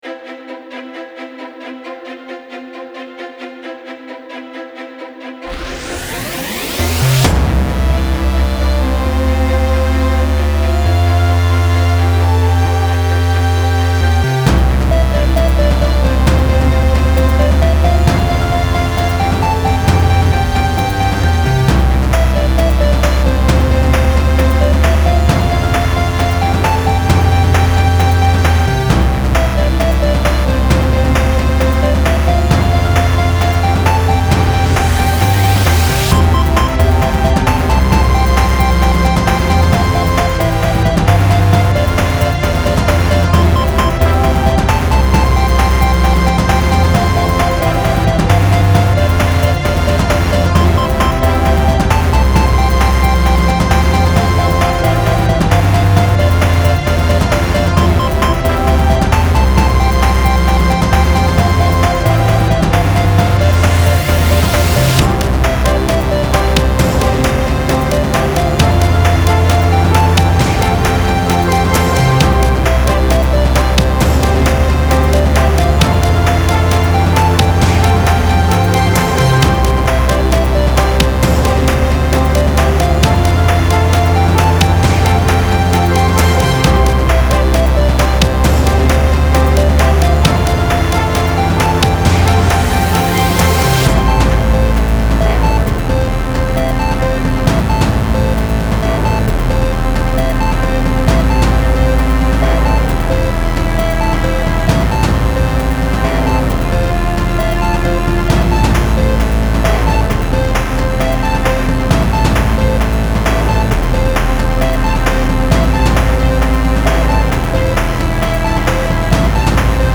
Style Style Chiptune, EDM/Electronic, Orchestral +1 more
Mood Mood Bright, Epic, Uplifting
Featured Featured Bass, Brass, Drums +2 more
BPM BPM 133